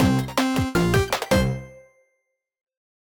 shard_get.ogg